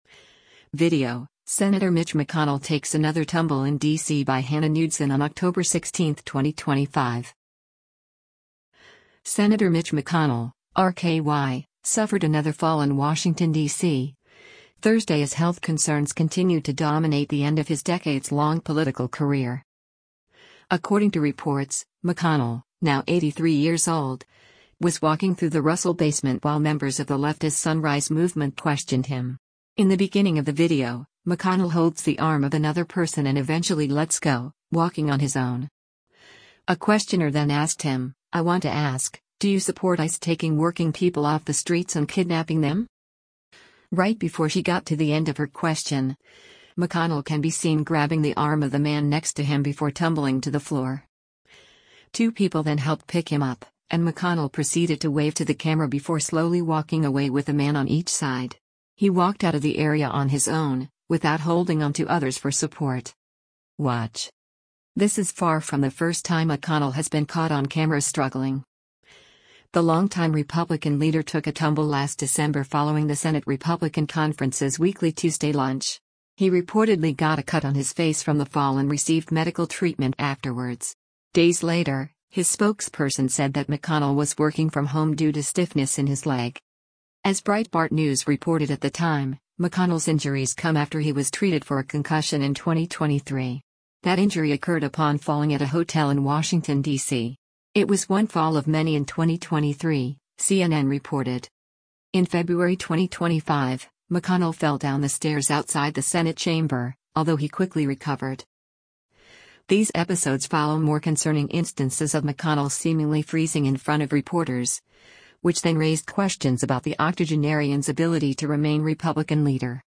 According to reports, McConnell — now 83 years old — was walking through the Russell basement while members of the leftist Sunrise Movement questioned him.
A questioner then asked him, “I want to ask, do you support ICE taking working people off the streets and kidnapping them?”